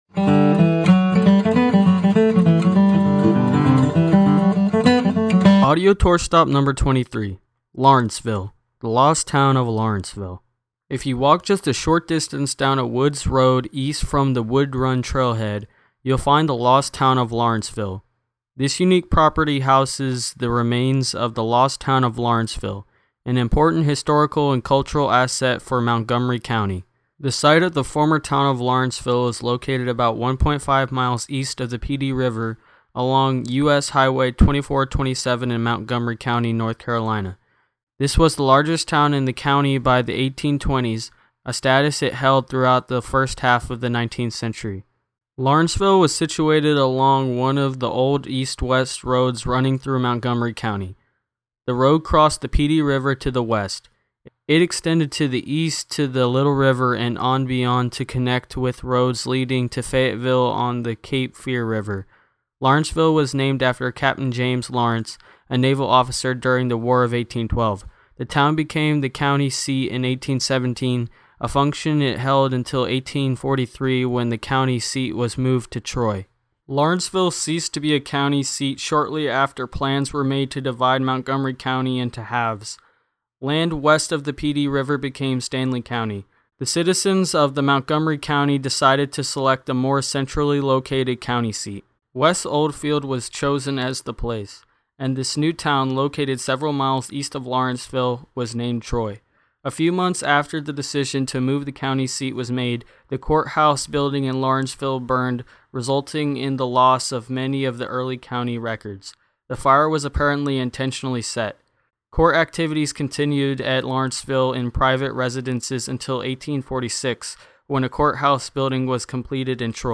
UT Audio Tour